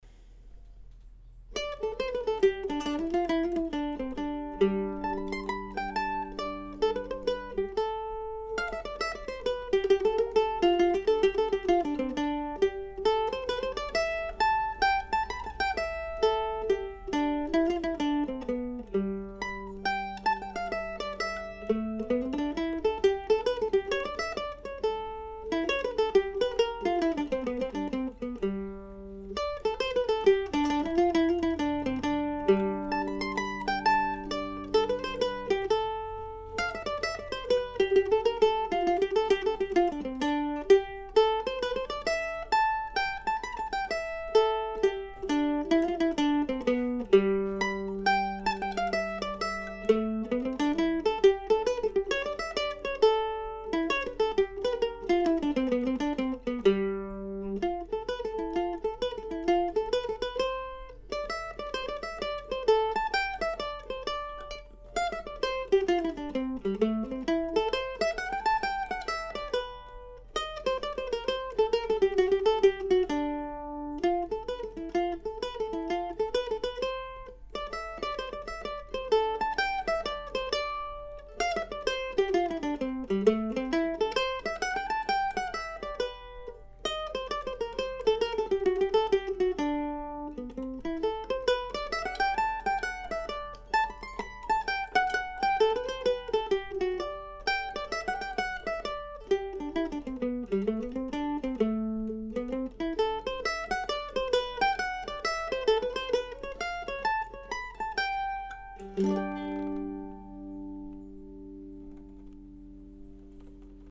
I don't often write for solo mandolin in a "classical" context, although my ongoing series of Deer Tracks pieces are the exception to that rule.